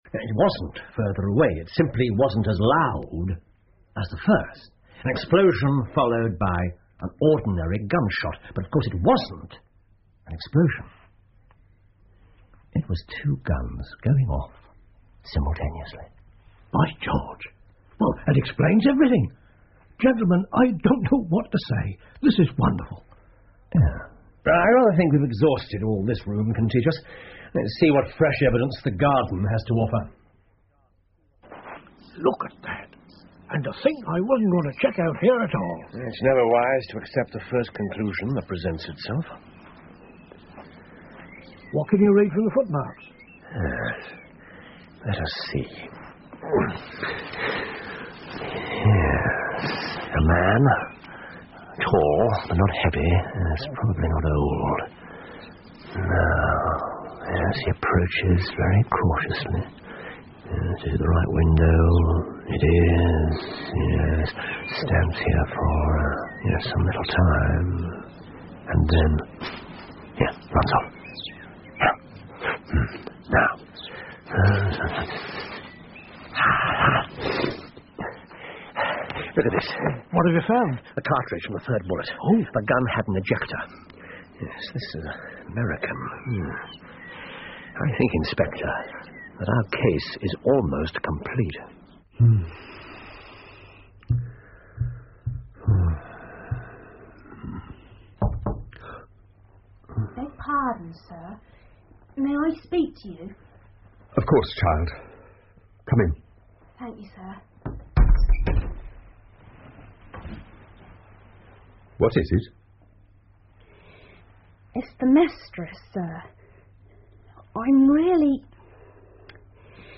福尔摩斯广播剧 The Dancing Men 4 听力文件下载—在线英语听力室